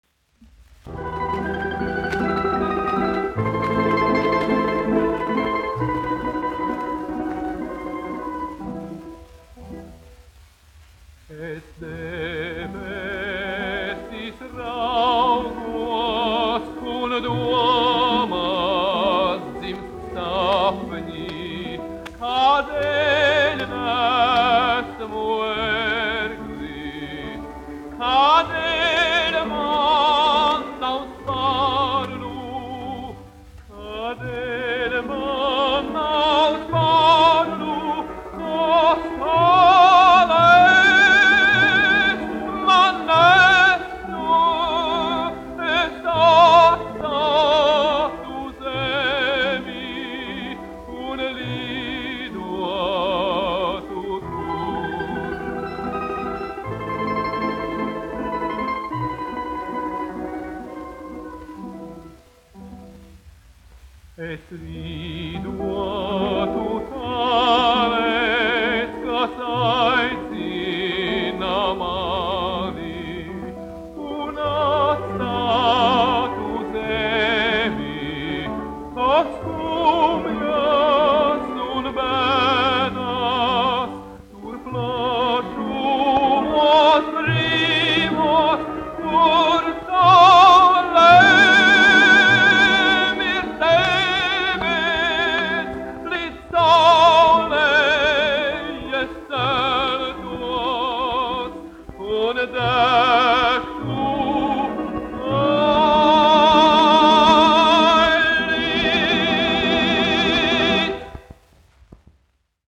1 skpl. : analogs, 78 apgr/min, mono ; 25 cm
Ukraiņu tautasdziesmas
Latvijas vēsturiskie šellaka skaņuplašu ieraksti (Kolekcija)